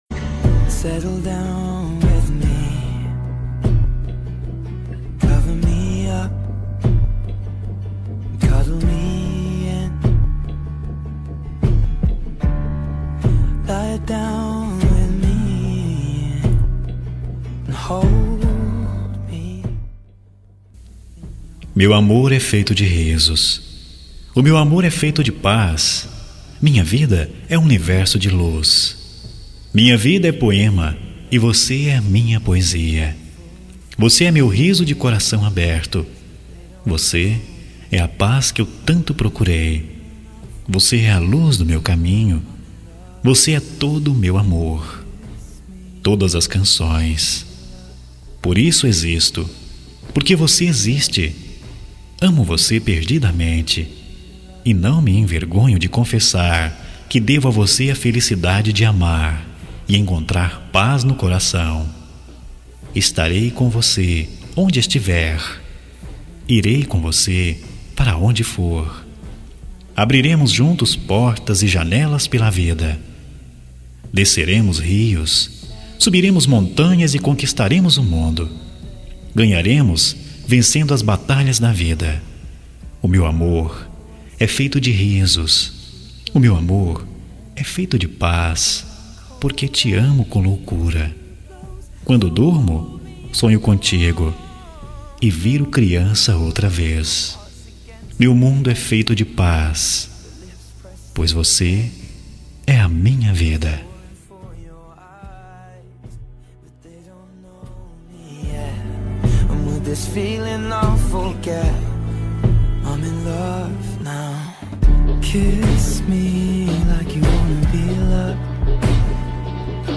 Voz Masculino